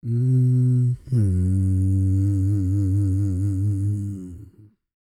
E-CROON P316.wav